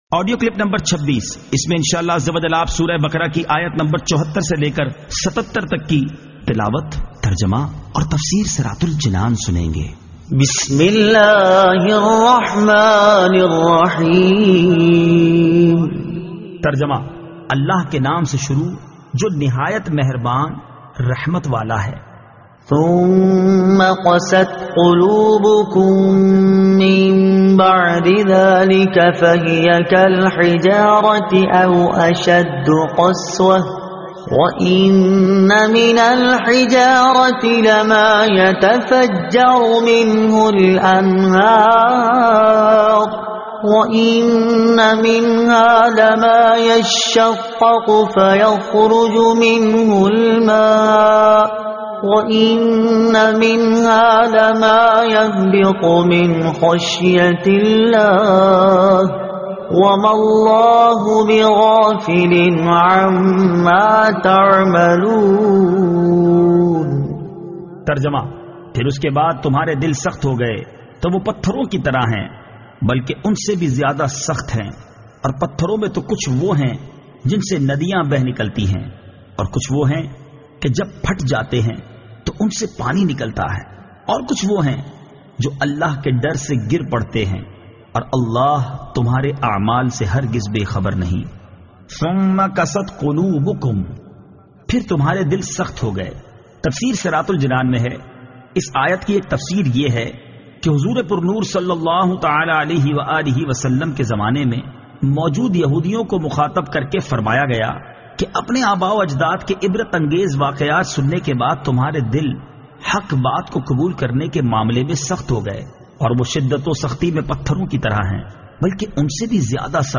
Surah Al-Baqara Ayat 74 To 77 Tilawat , Tarjuma , Tafseer